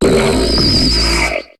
Cri de Limonde dans Pokémon HOME.